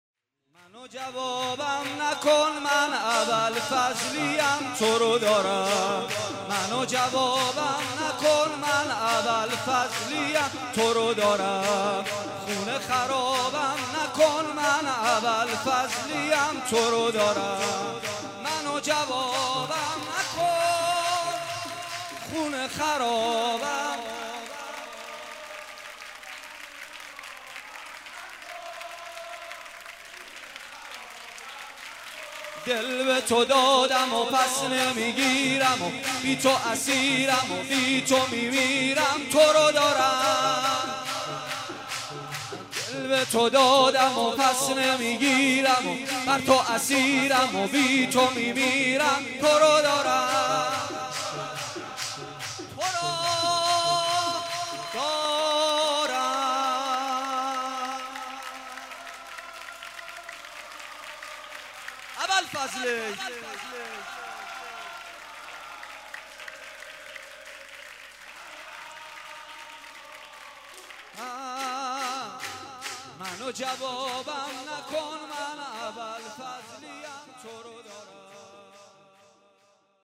به مناسبت سالروز ولادت حضرت عباس(ع)
مداحی